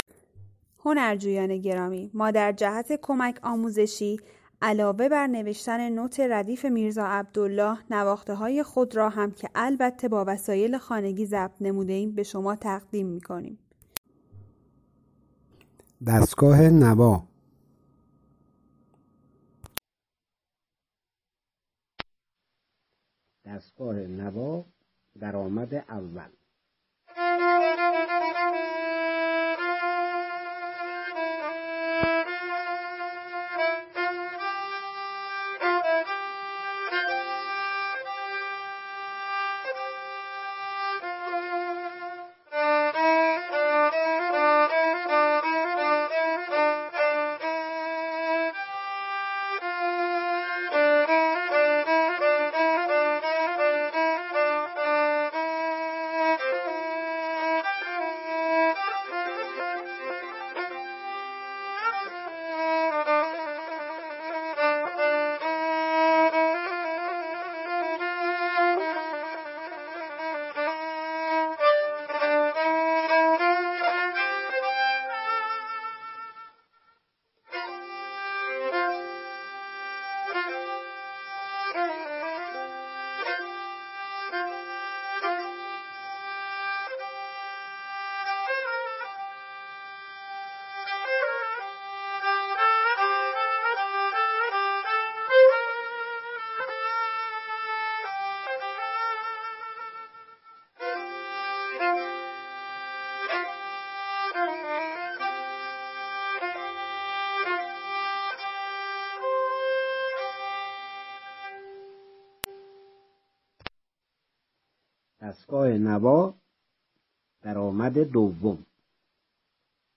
ساز : کمانچه